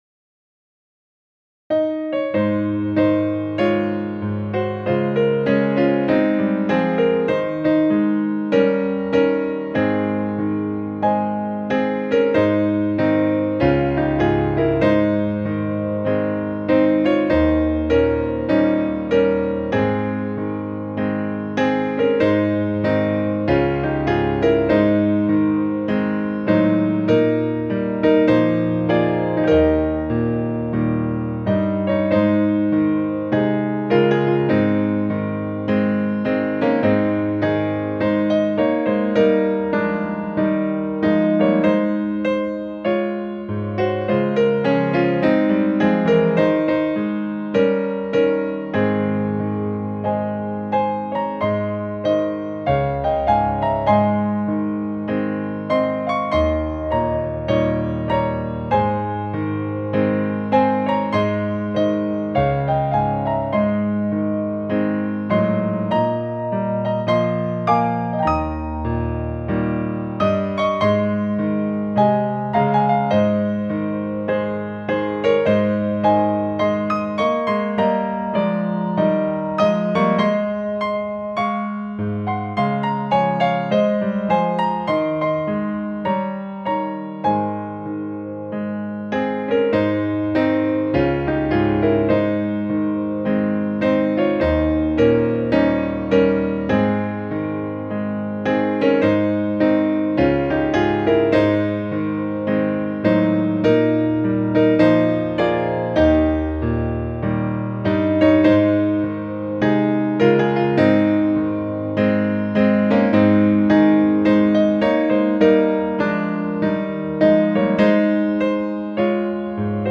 This is one of the happiest sounding hymns in the whole hymnal.
The hymn accompaniments that you are listening to are part of the final project.